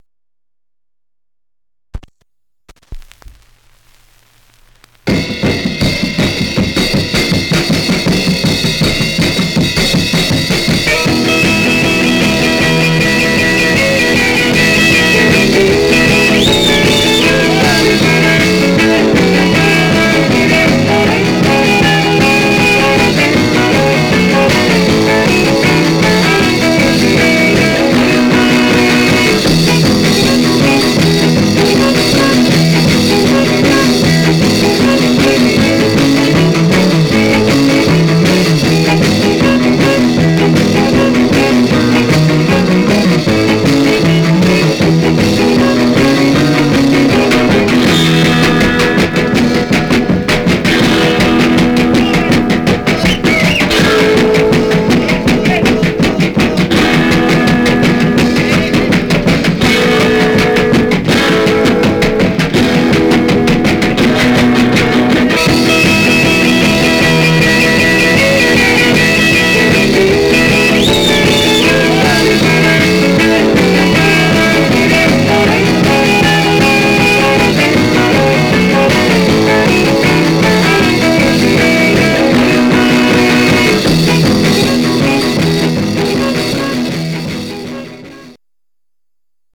Surface noise/wear
Mono
Rockabilly Condition